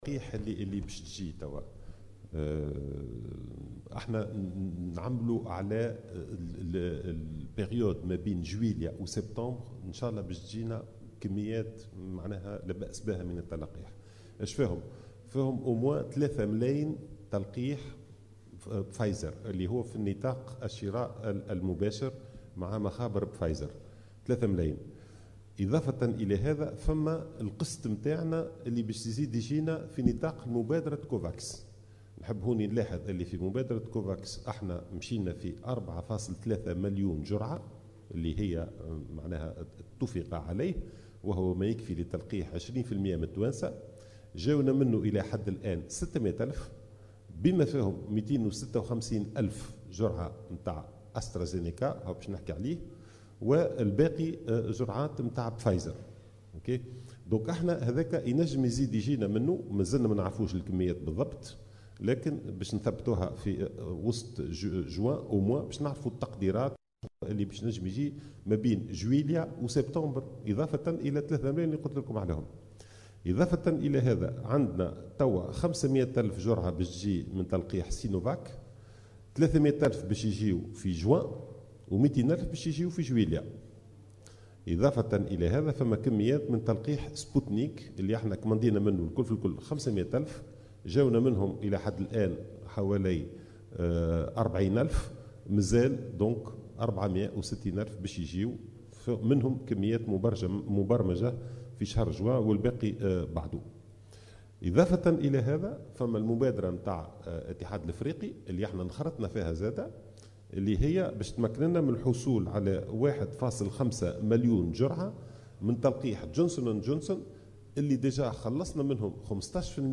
وأضاف خلال ندوة صحفية انعقدت، اليوم الجمعة، أنه من المنتظر وصول 3 ملايين جرعة من لقاح"فايز" في نطاق الشراء المباشر مع مخابر "فايزر"، إضافة إلى وصول قسط جديد من اللقاحات في إطار مبادرة "كوفاكس" ( تم الاتفاق على تسلّم 4.3 مليون جرعة مليون جرعة وهو ما يكفي لتلقيح 20 بالمائة من التونسيين، تسلمت منهم تونس إلى حدّ الآن 600 ألف جرعة بما فيها 256 ألف من لقاح "استرازينيكا" والباقي جرعات من لقاح " فايزر").